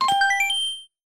The Save Block sound effect from Paper Mario
Self recorded using the GameShark code 802488BB 00A0 to disable the music in the Toad Town central area
Category:Sound effect media files